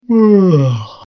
Yawn 02
Yawn_02.mp3